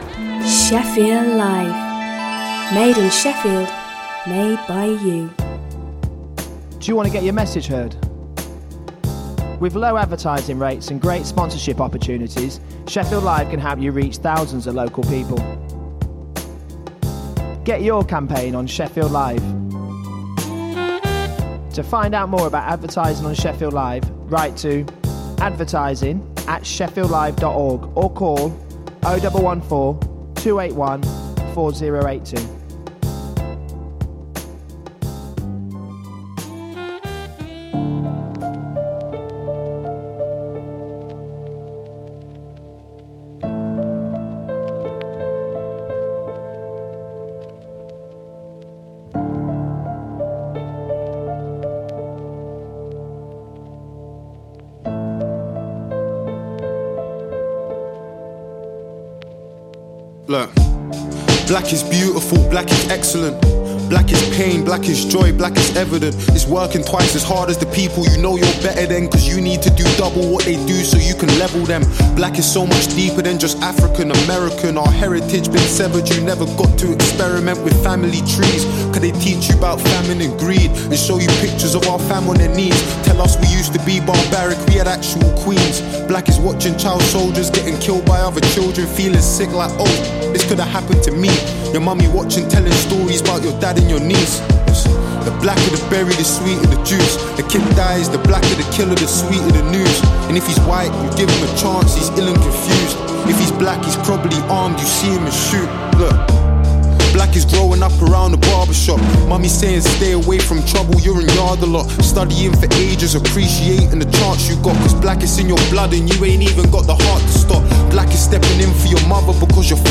Magazine programme for the Yemeni community